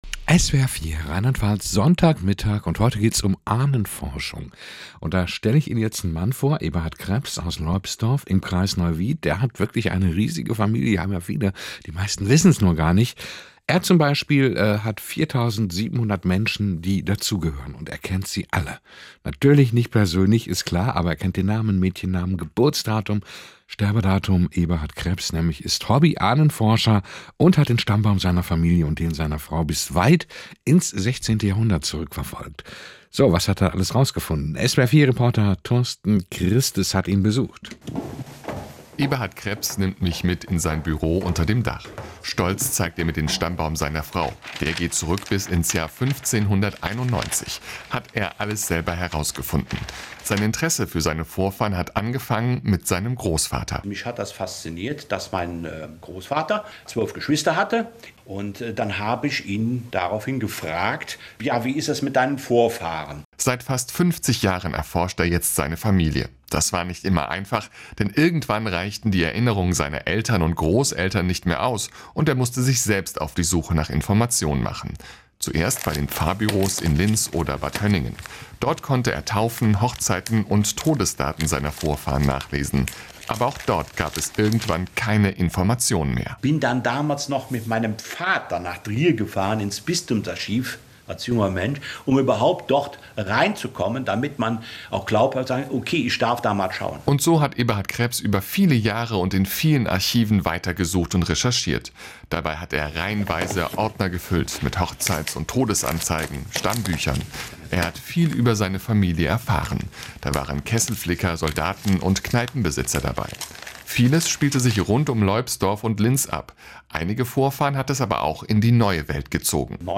Kurzbericht im Radiosender SWR 4 am Sonntag, den 16.05.2021 über mein Hobby Ahnenforschung
Mitschnitt SWR4.MP3